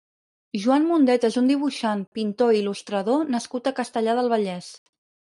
Pronounced as (IPA) [ʒuˈan]